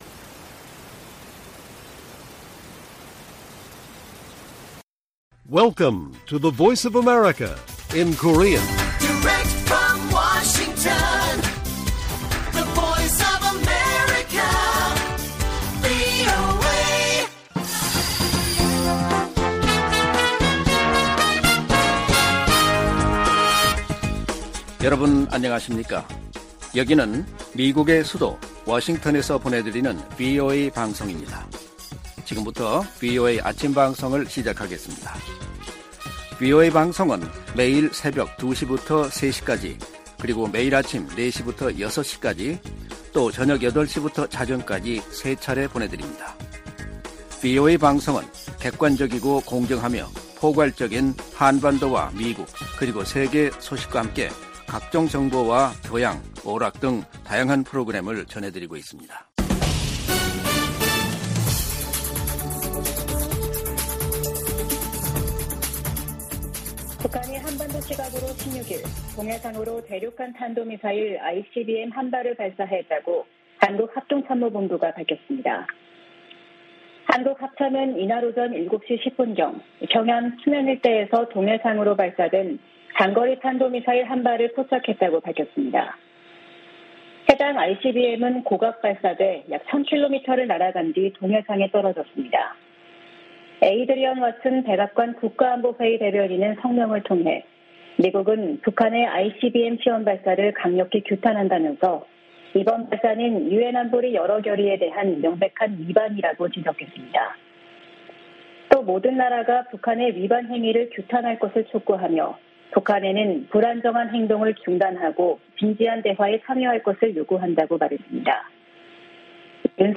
세계 뉴스와 함께 미국의 모든 것을 소개하는 '생방송 여기는 워싱턴입니다', 2023년 3월 17일 아침 방송입니다. '지구촌 오늘'에서는 흑해 상공에서 미군 드론이 러시아 전투기의 도발로 추락한 사건에 관해 양국 국방장관이 통화한 소식 전해드리고, '아메리카 나우'에서는 미국 정부가 '틱톡' 측에 중국 모회사 지분을 매각하라고 요구한 이야기 살펴보겠습니다.